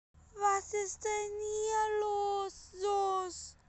was ist denn hier los sos Meme Sound Effect
This sound is perfect for adding humor, surprise, or dramatic timing to your content.